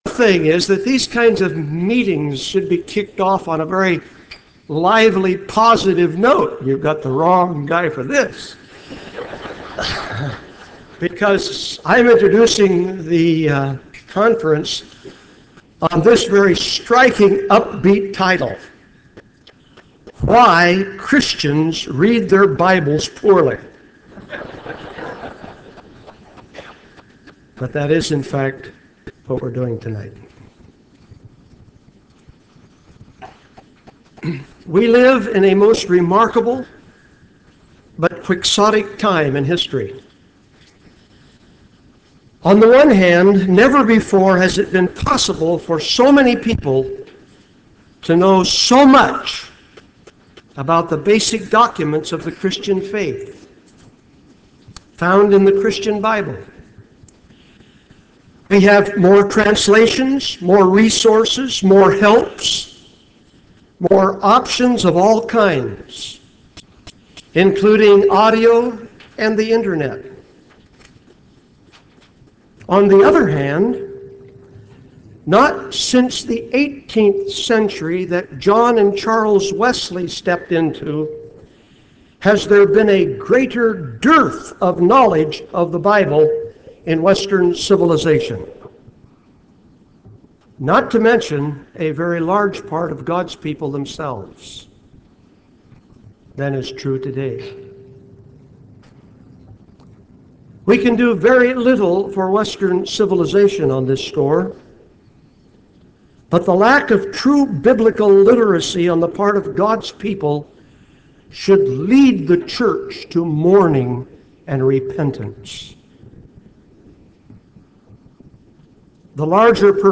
Schedule for the For All It's Worth Conference held April 15-16, 2005
MP3 for Plenary 1 by Gordon Fee